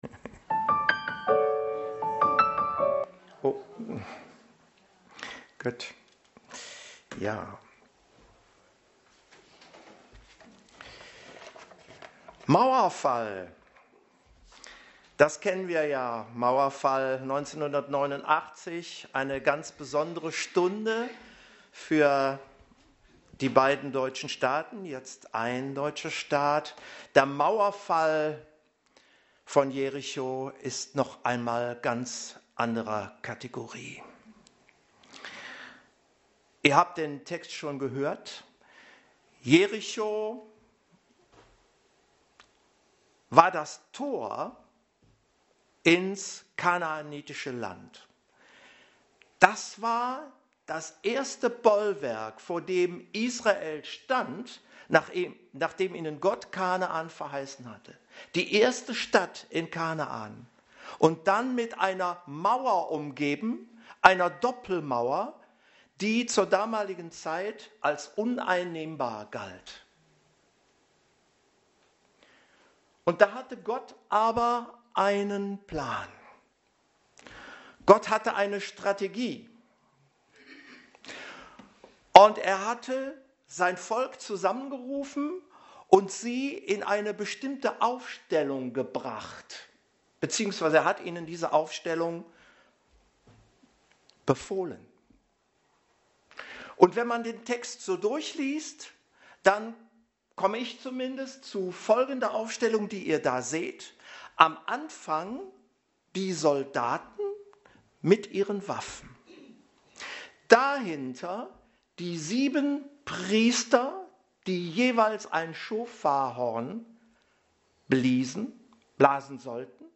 Passage: Josua 6,1-20 Dienstart: Predigt